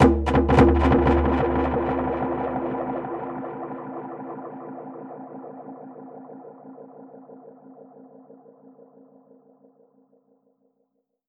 Index of /musicradar/dub-percussion-samples/85bpm
DPFX_PercHit_C_85-03.wav